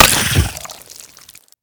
biter-death-2.ogg